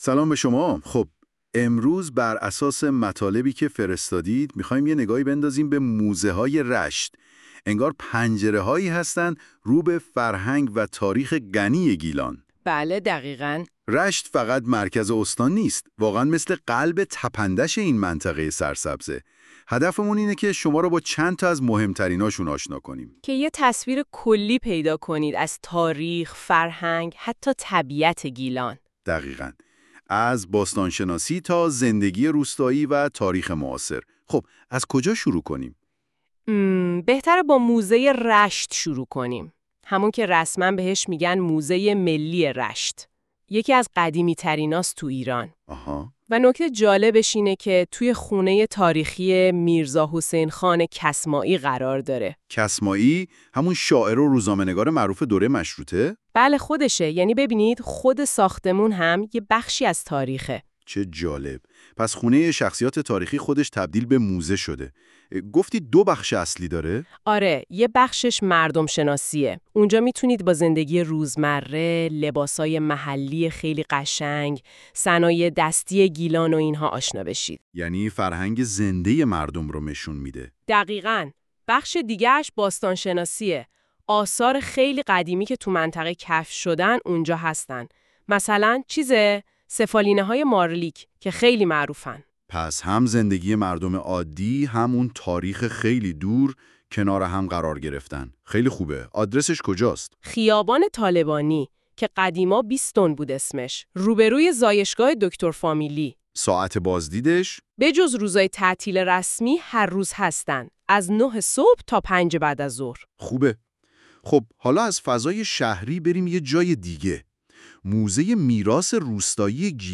این خلاصه صوتی به صورت پادکست و توسط هوش مصنوعی تولید شده است.